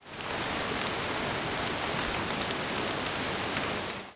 rain4.ogg